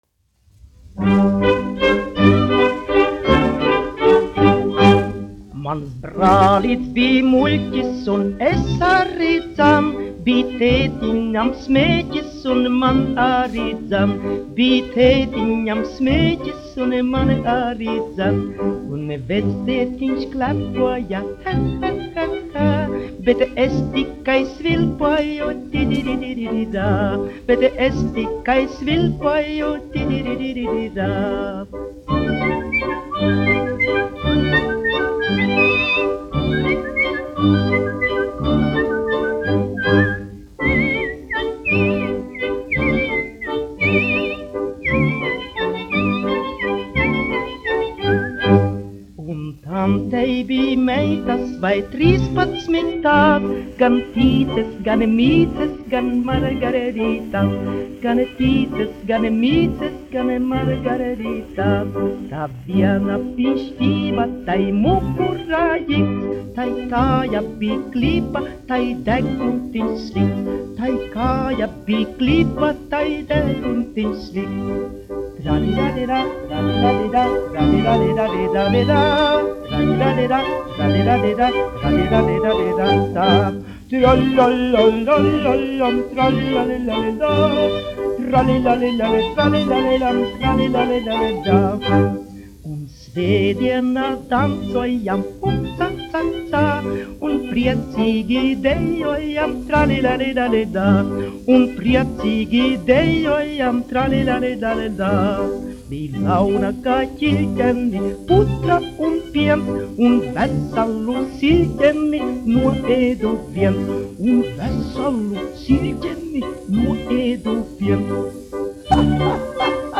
1 skpl. : analogs, 78 apgr/min, mono ; 25 cm
Dziesmas (augsta balss) ar instrumentālu ansambli
Humoristiskās dziesmas
Latvijas vēsturiskie šellaka skaņuplašu ieraksti (Kolekcija)